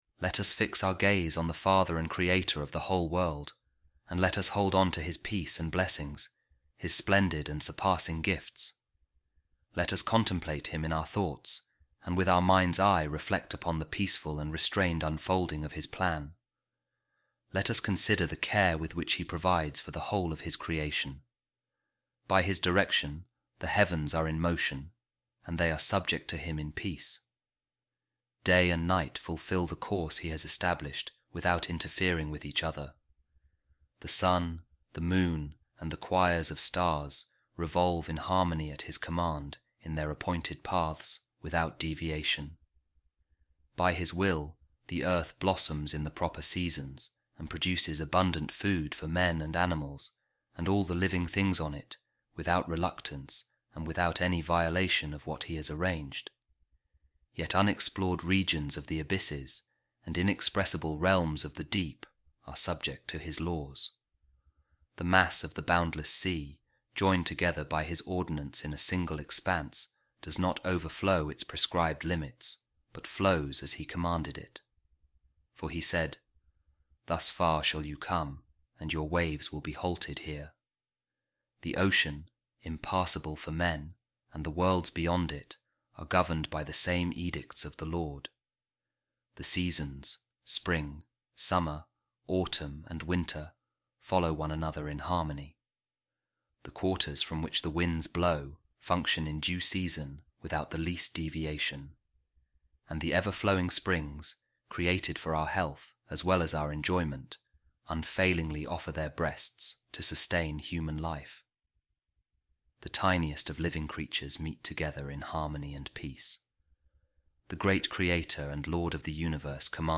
A Reading From The Letter Of Pope Saint Clement I To The Corinthians | The Lord Of The Universe Has Enjoined Peace And Harmony For The Good Of All Alike